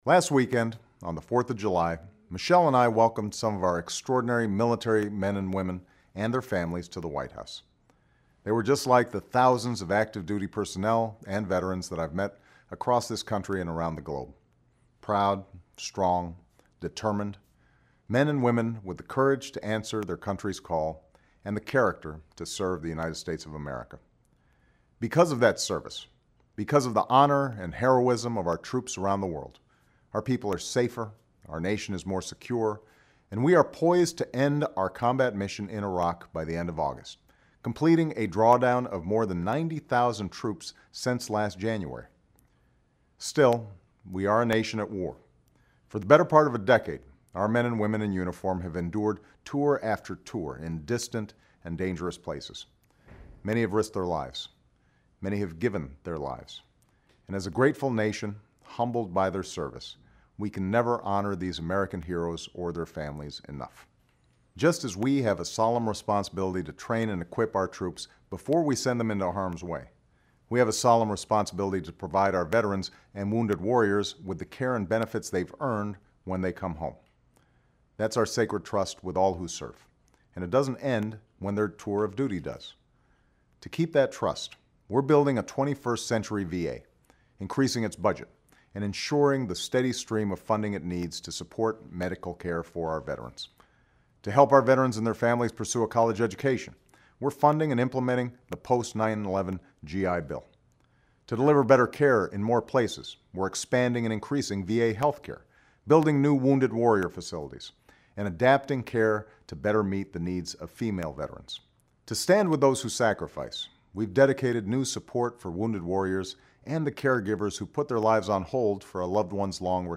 Barack Obama's weekly radio addresses (and some other speeches).